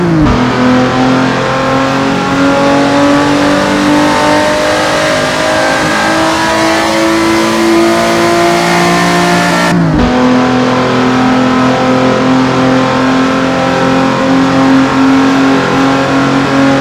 fourth_cruise.wav